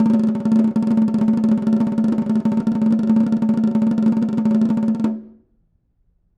Snare2-rollNS_v3_rr1_Sum.wav